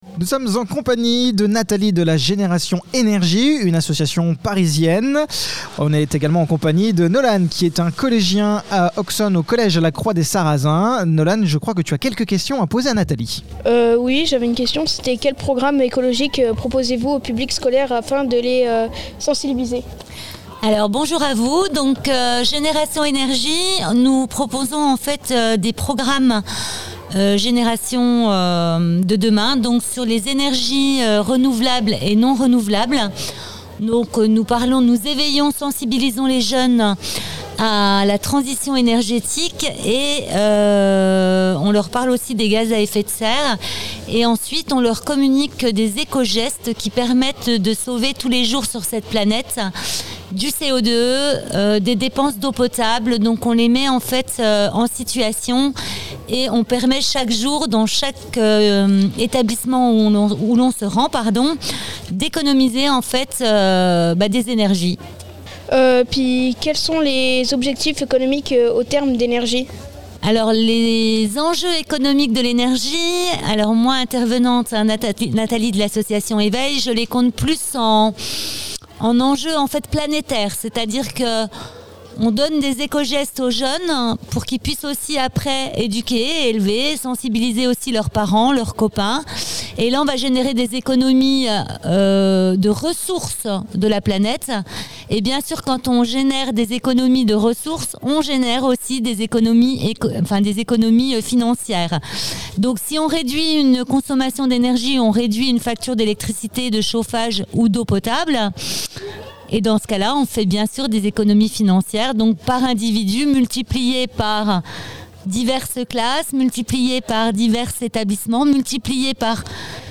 Interview de Génération Energie